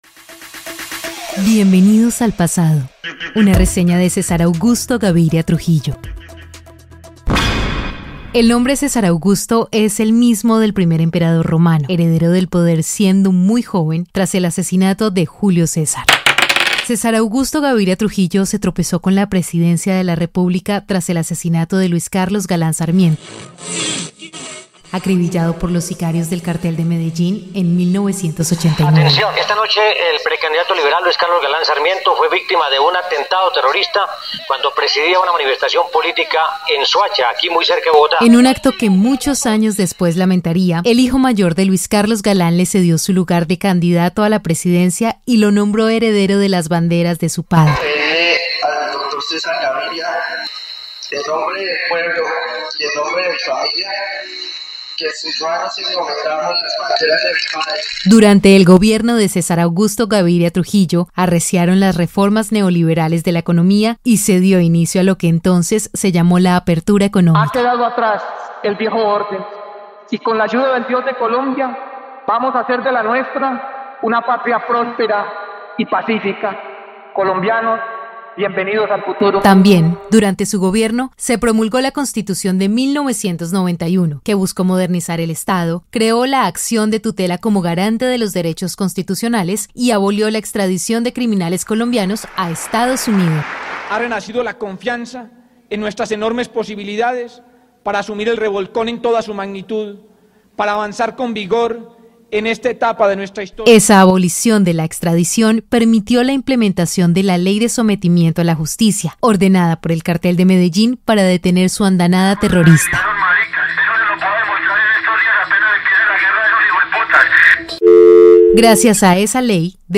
Un viaje al pasado para entender a una de las figuras más influyentes y polémicas de Colombia: César Gaviria Trujillo. Este perfil sonoro repasa su inesperado ascenso a la presidencia tras el magnicidio de Luis Carlos Galán, y examina las dos caras de su gobierno: la modernización del Estado con la Constitución de 1991 y la controvertida prohibición de la extradición que culminó en la irrisoria entrega de Pablo Escobar.